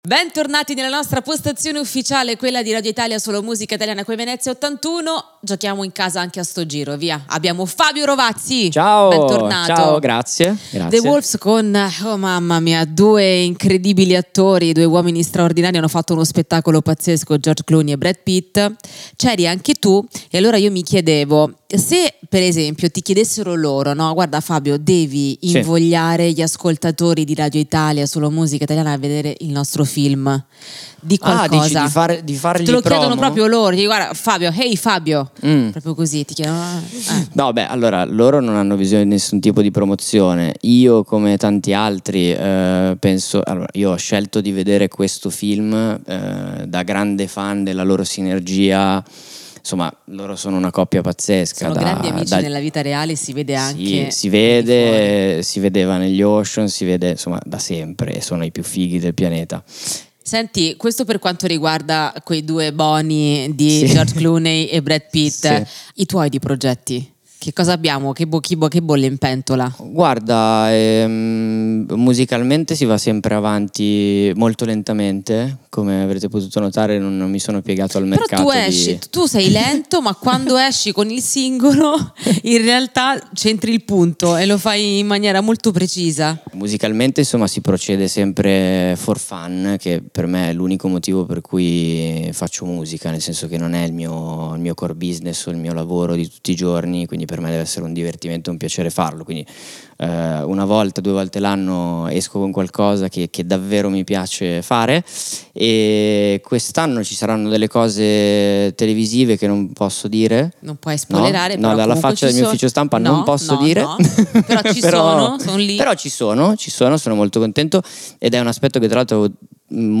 L'artista è ospite a Radio Italia solomusicaitaliana all'81esima Mostra del Cinema di Venezia
Intervista a Fabio Rovazzi del 04/09/2024